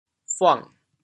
潮阳拼音“buang6”的详细信息
buang6.mp3